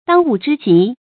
注音：ㄉㄤ ㄨˋ ㄓㄧ ㄐㄧˊ
當務之急的讀法